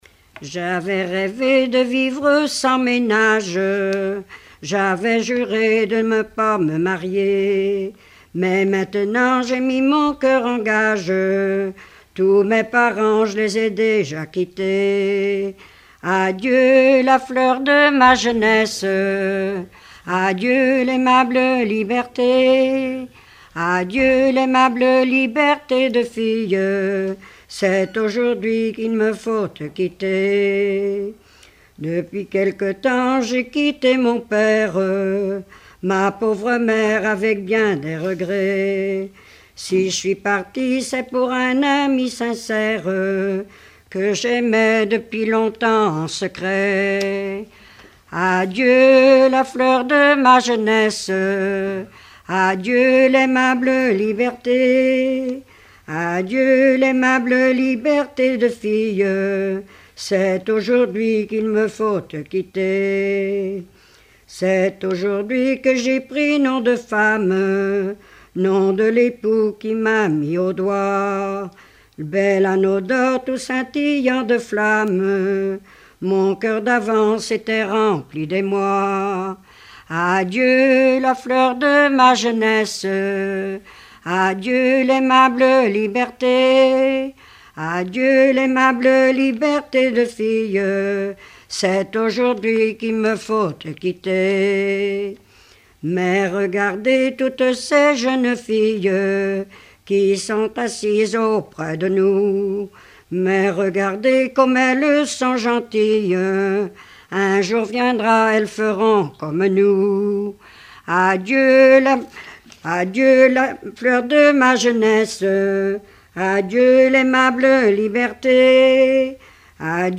circonstance : fiançaille, noce
Genre strophique
Regroupement de chanteurs du canton
Pièce musicale inédite